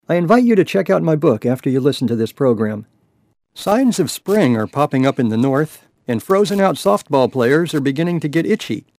The first three seconds are the end of the canned intro. The last six seconds are the beginning of a particular episode. The sound in this second part is bad, and I don't know why.
I'm using an MXL V57M microphone with pop filter and foam mic cover in a foam sound box, a Behringer 1204FX mixer, and an inexpensive HP desktop computer with the stock sound card.
Boy, my first impression was that it sounded like dirty contacts in the mic chain.
And the stock part of the file - the first three seconds - was recorded with the same setup but a different microphone, and that sounds fine.
Then I ran it through Levelator, and guess what - the fuzziness was back!